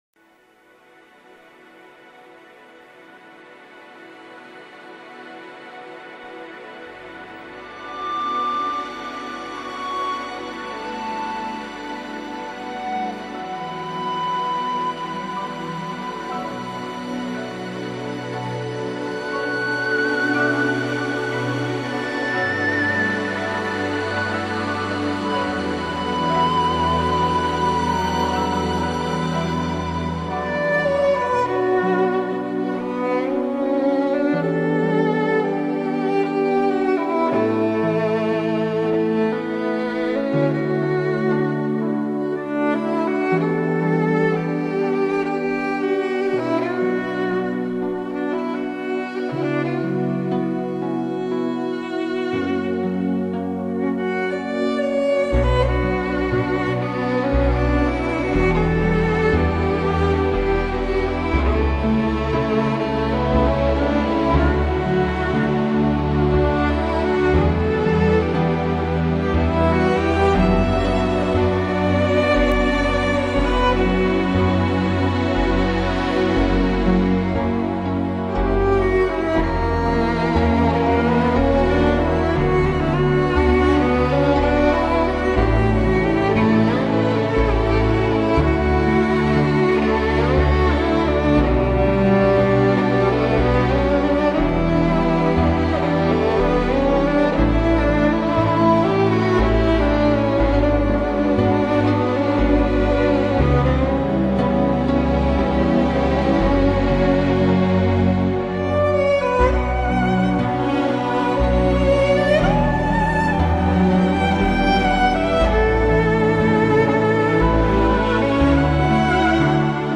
与前两张相比人声部分加大了
是乐队少有的大气一点的作品